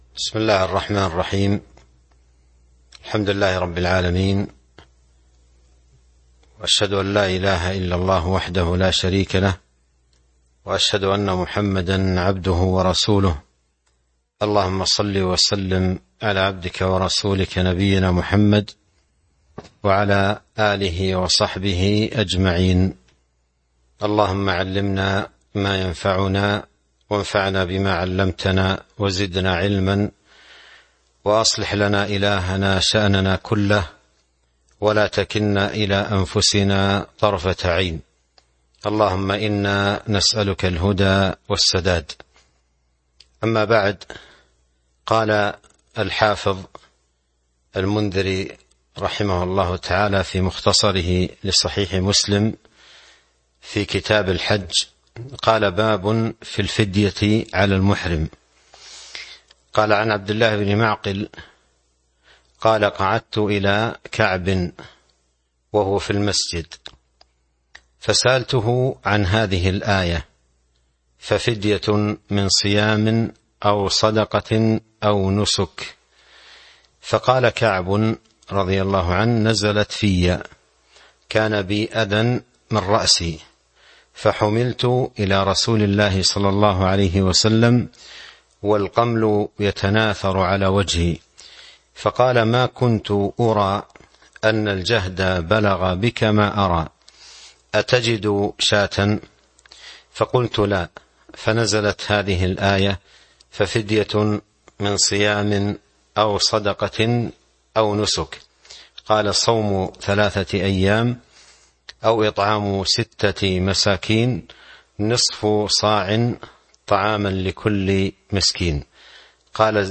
تاريخ النشر ١ محرم ١٤٤٢ هـ المكان: المسجد النبوي الشيخ